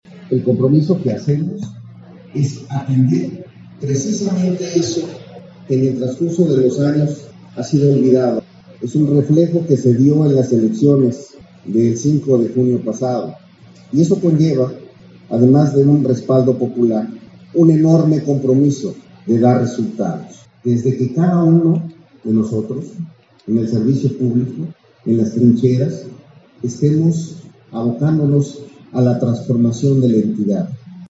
• Asistió al segundo Informe de Gobierno de Tenango de Doria
Tenango de Doria, Hgo; 10 de septiembre de 2022. Cercano al pueblo y a los municipios, el gobernador Julio Menchaca Salazar acudió al segundo Informe de Gobierno de Tenango de Doria, que encabeza Erick Mendoza Hernández, en un ejercicio democrático de transparencia y rendición de cuentas.